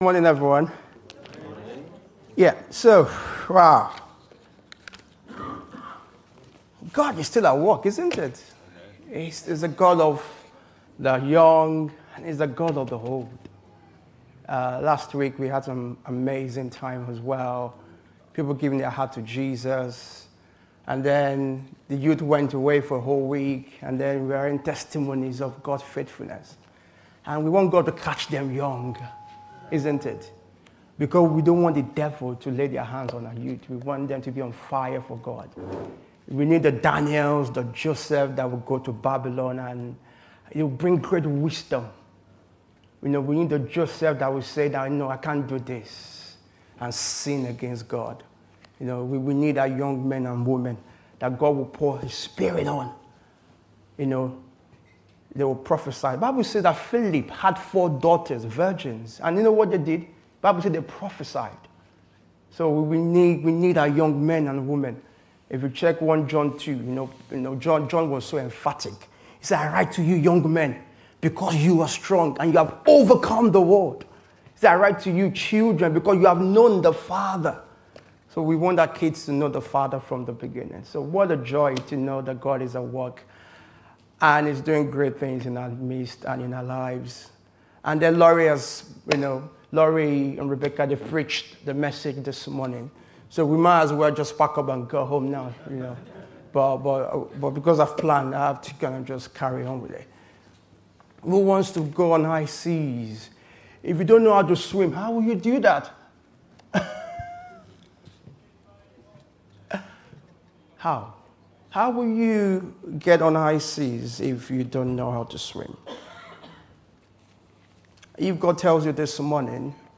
This sermon through the story of Abraham calls us to embrace times where our faith is tested.